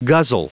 Prononciation du mot guzzle en anglais (fichier audio)
Prononciation du mot : guzzle